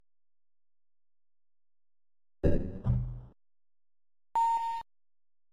File:Doorbell (retro).mp3